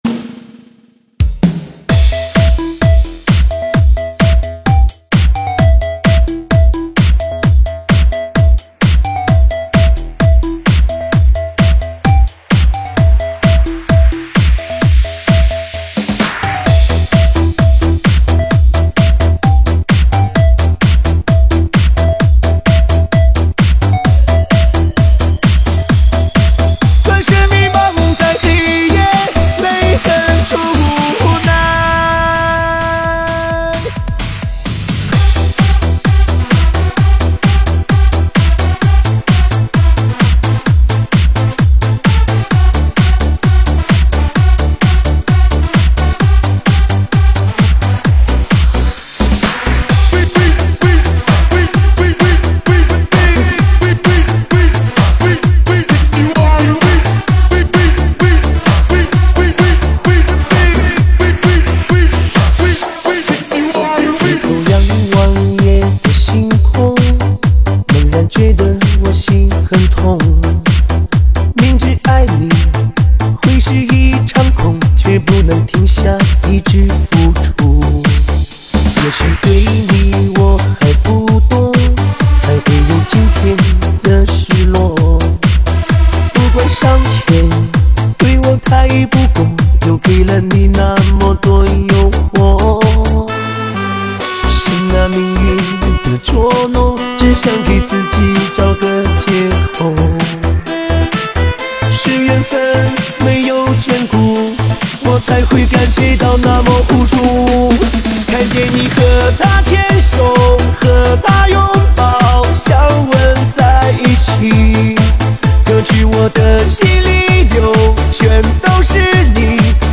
栏目：中文舞曲